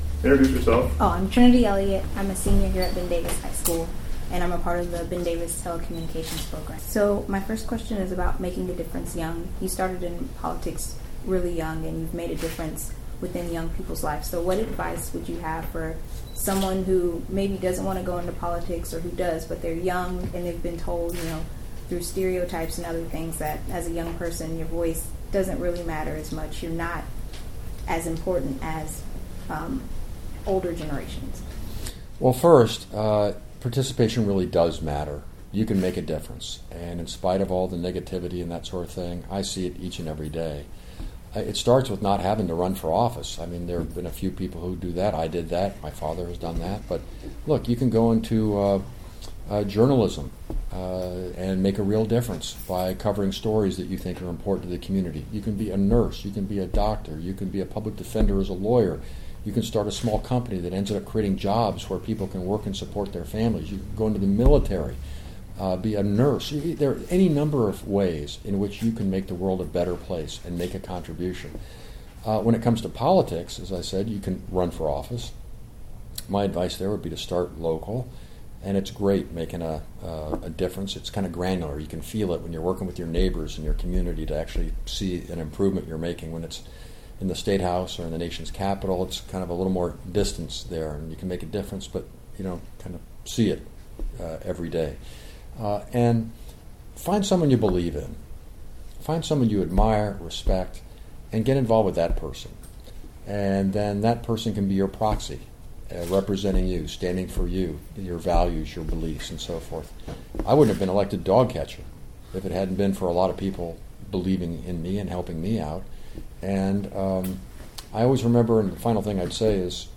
Evan Bayh Interview